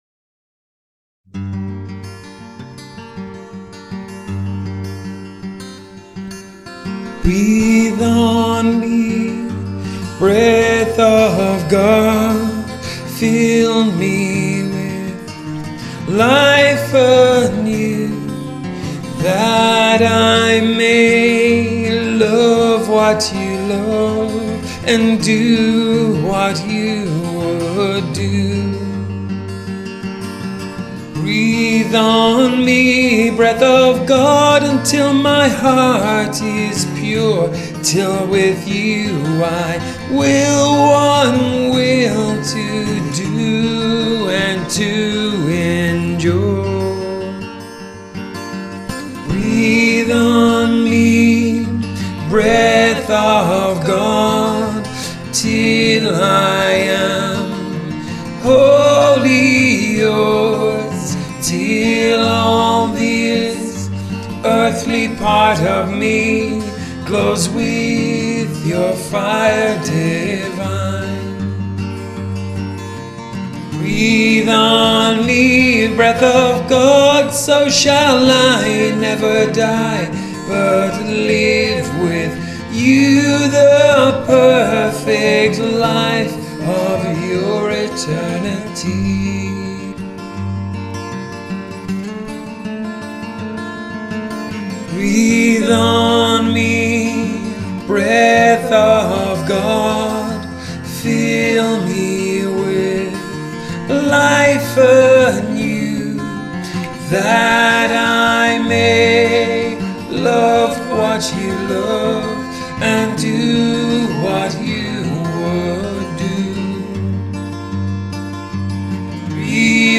Click Here to Singalong with  the MMM SALt Choir: